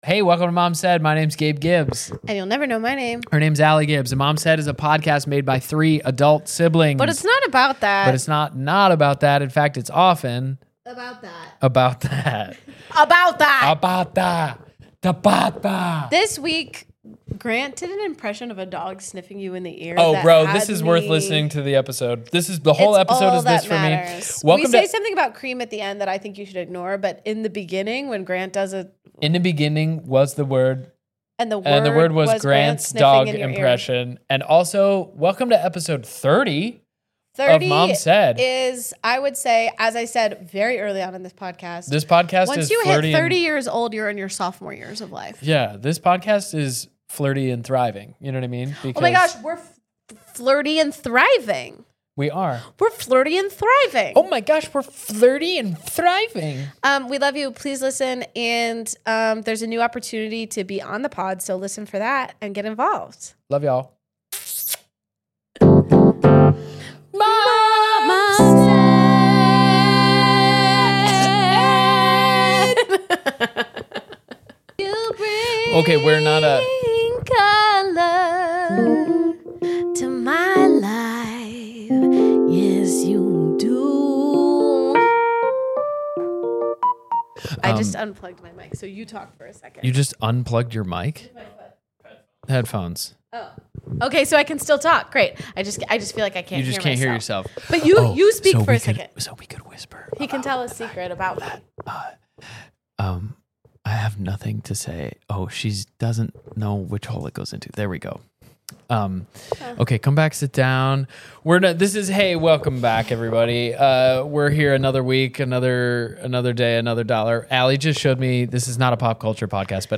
This week the siblings talk about newsie, dogs that smell time, and make way too big of a deal of this being the 30th ep.